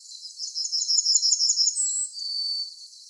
Yellowhammer Dialects
Locality Tonden, Netherlands